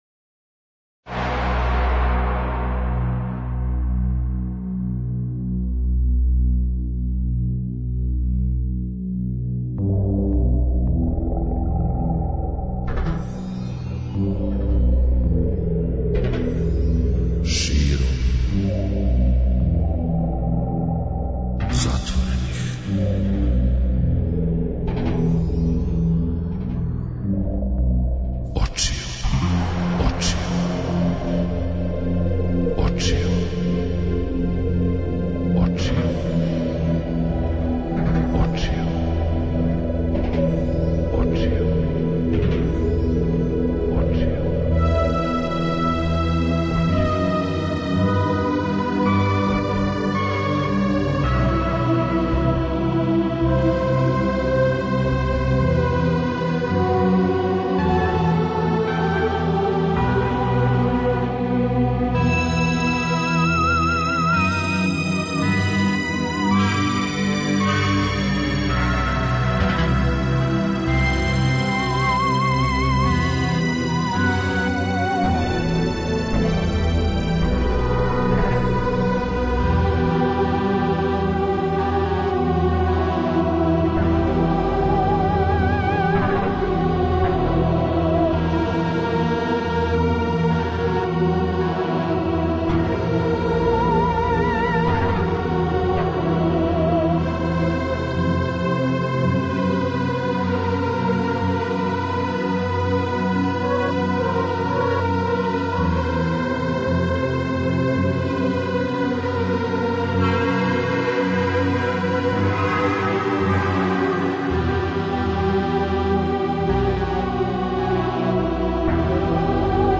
У ноћи између среде и четвртка, од поноћи, па до четири ујутру, у оквиру ноћног програма Широм затворених очију, бирамо 202 најбоље песме Југословенске рок музике од 1961. до 1991. године по избору слушалаца Београда 202.